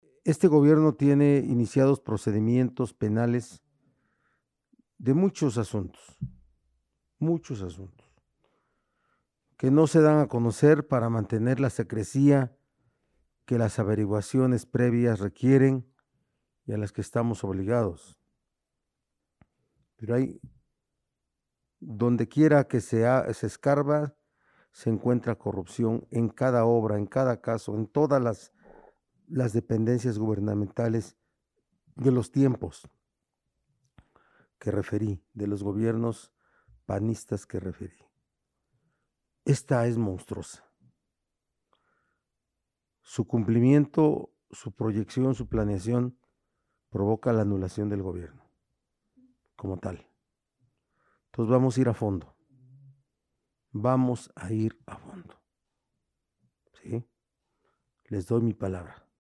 En conferencia de prensa se informó que la construcción de la línea 1 de RUTA costó mil 464 millones de pesos, cifra que, analizando la información, fue inflada y mezclada en distintos rubros para ocultar robos millonarios.